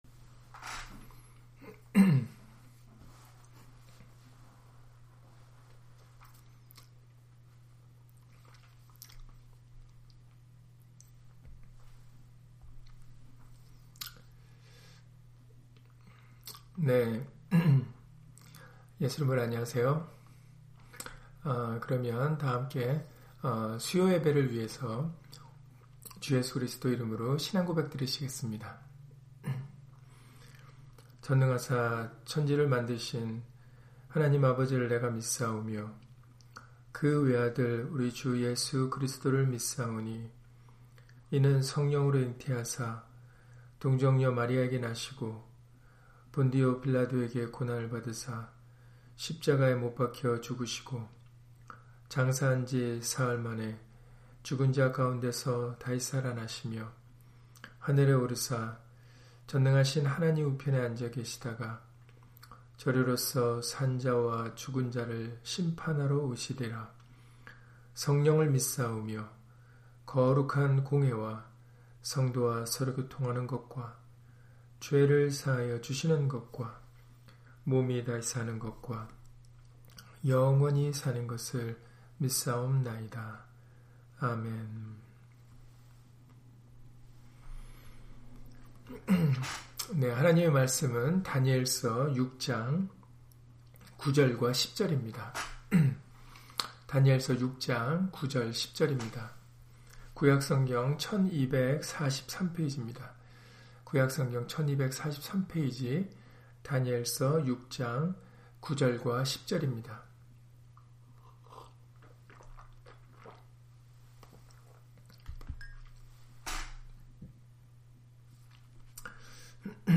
다니엘 6장 9-10절 [알고도 전에 행하던대로] - 주일/수요예배 설교 - 주 예수 그리스도 이름 예배당